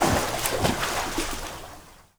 SPLASH_Movement_05_mono.wav